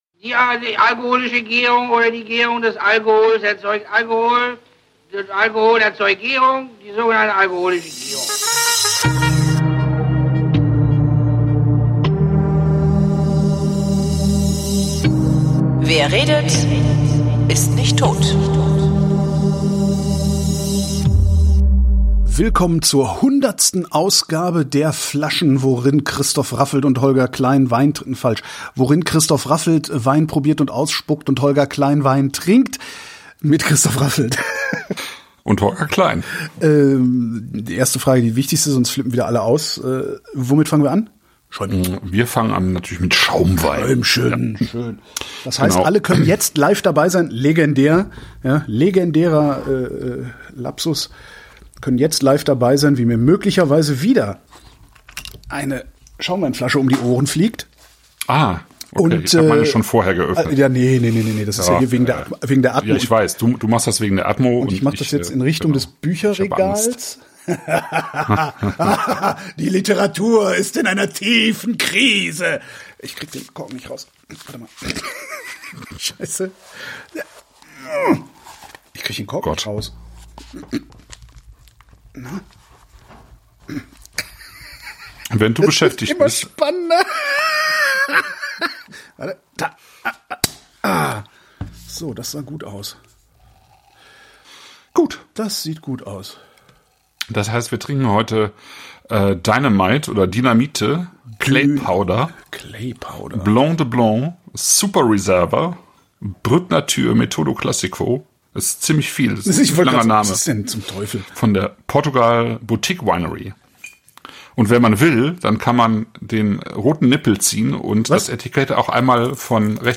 wer live dabei war, hat sogar den Erstickungsanfall mitbekommen, der hier nicht mehr zu hören ist.